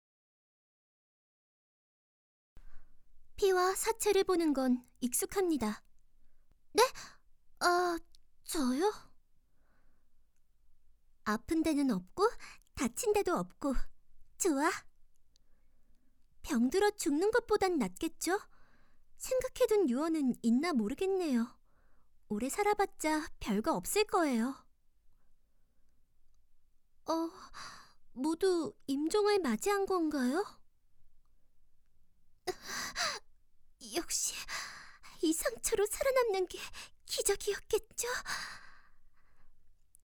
角色配音